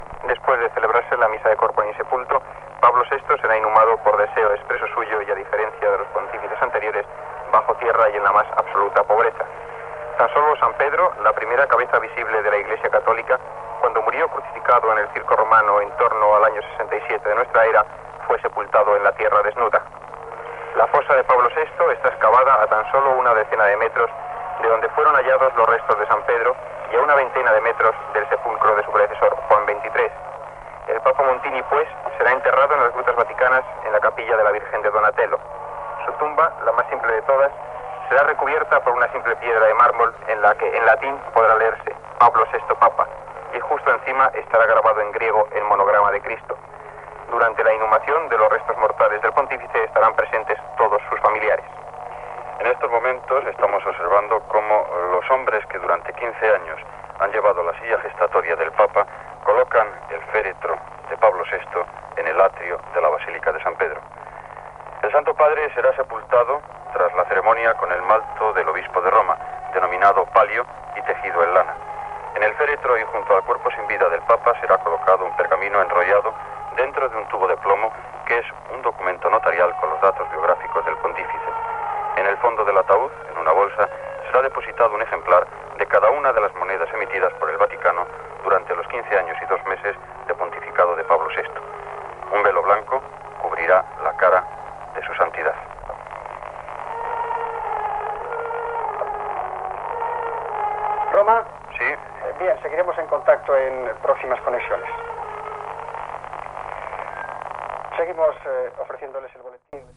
Informació, des de la plaça de Sant Pere de la Ciutat del Vaticà, de com serà el funeral i l'enterrament del Sant Pare Pau VI (Giovanni Battista Maria Montini)
Informatiu